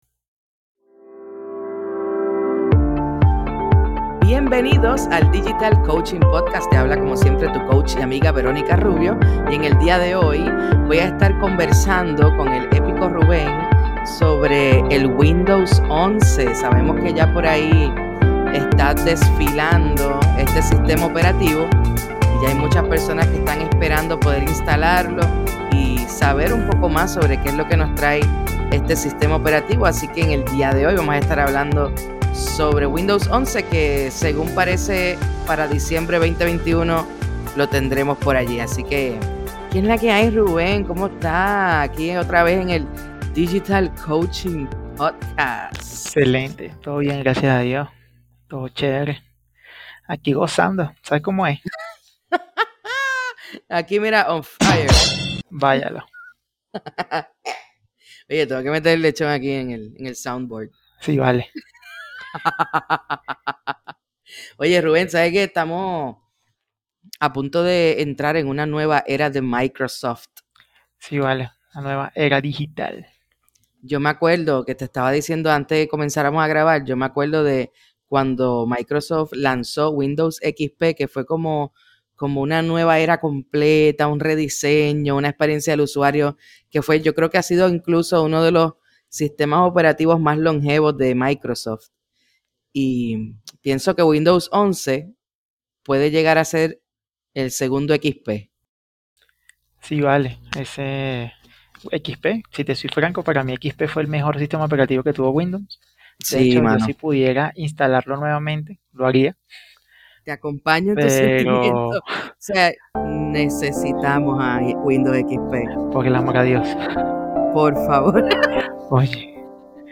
Disfruta de una conversación amena